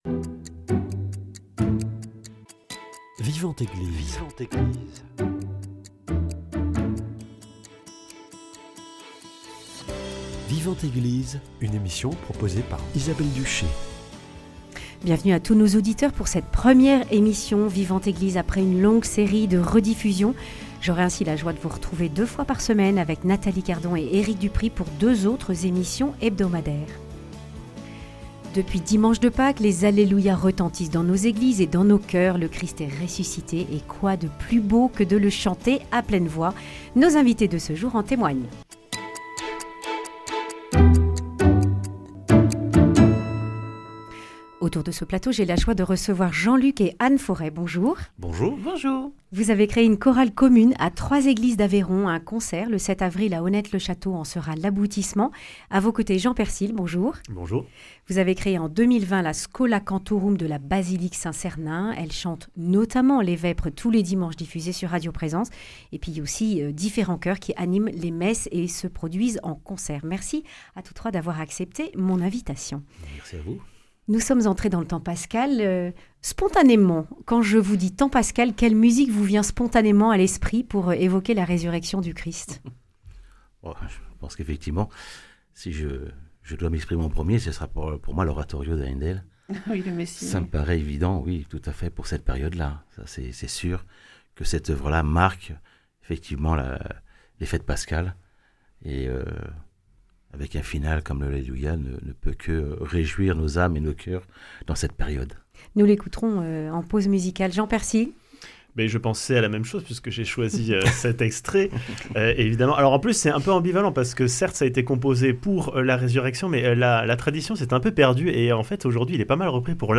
En ce temps pascal, nos invités débattent de la juste place du chant dans la liturgie.